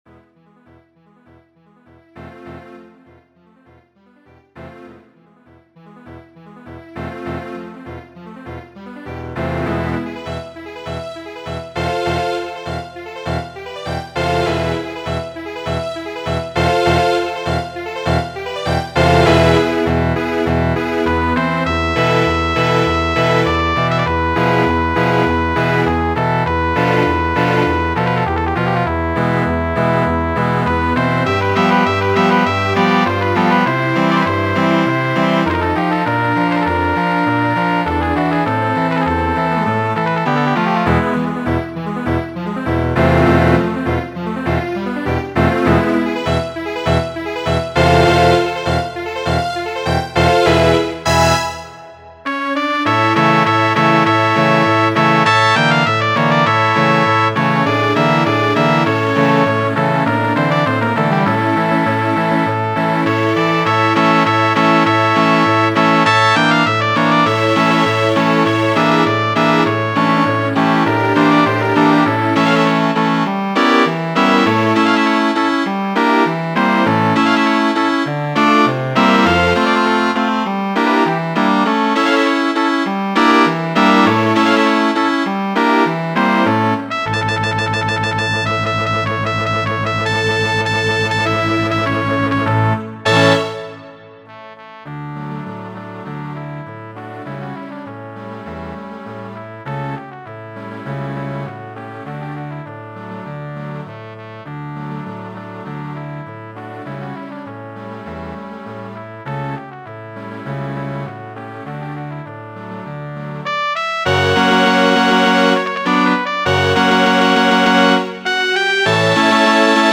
Hispanio cañí, fama paŝo duobla.